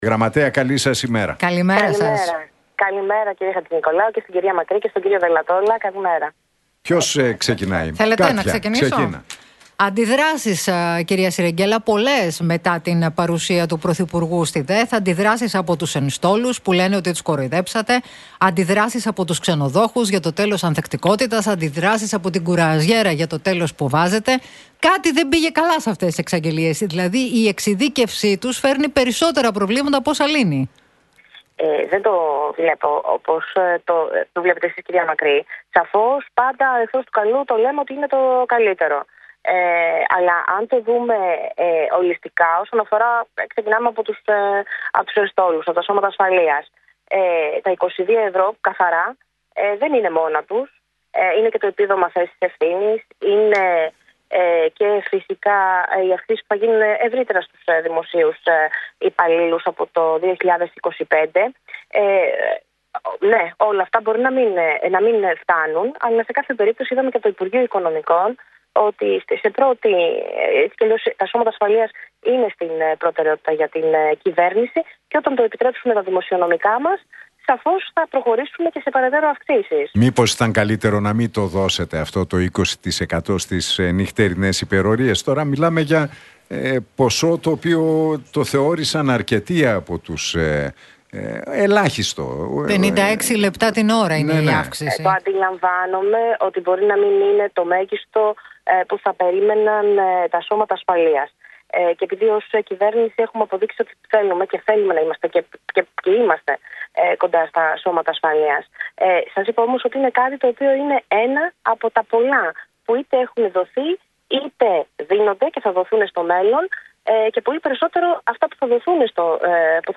Συρεγγέλα στον Realfm 97,8: Θα προχωρήσουμε σε περαιτέρω αυξήσεις στους ενστόλους όταν το επιτρέψουν τα δημοσιονομικά μας